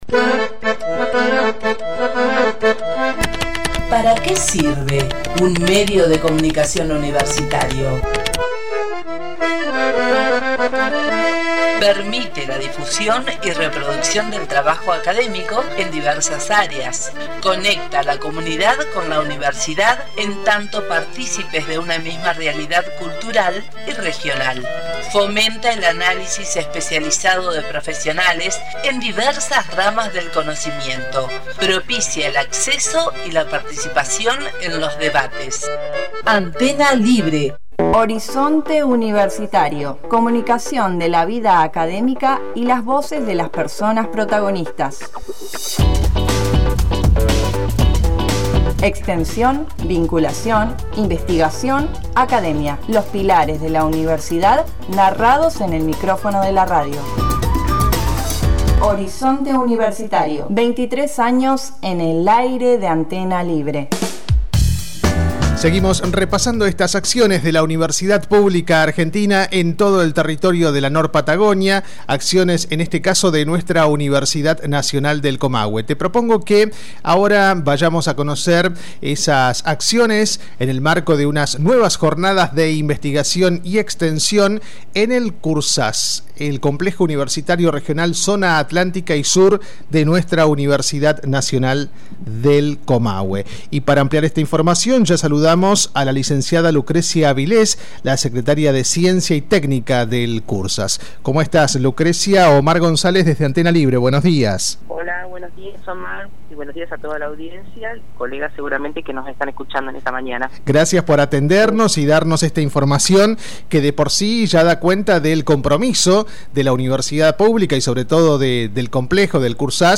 Este era el diálogo que mantenía con el micrófono de Horizonte Universitario